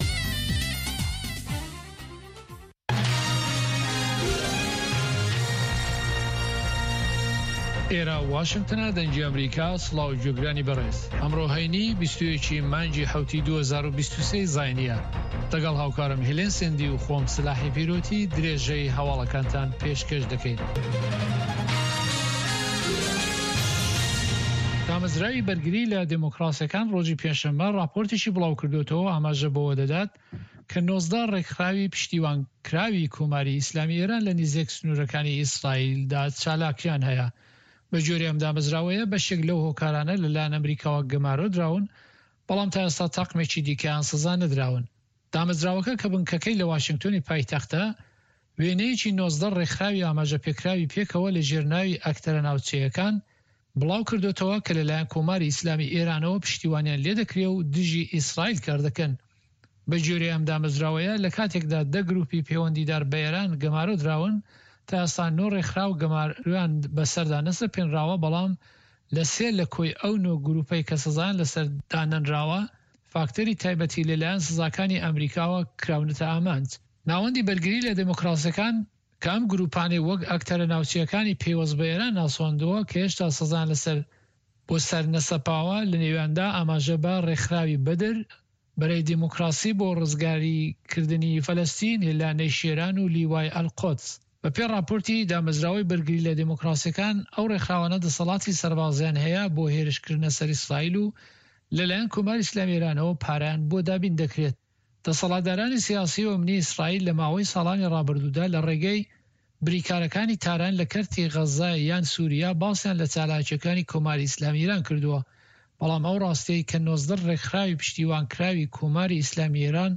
هەواڵەکانی 3 ی پاش نیوەڕۆ